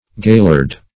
Search Result for " gaillard" : The Collaborative International Dictionary of English v.0.48: Gaillard \Gail`lard"\, a. [F. See Galliard .]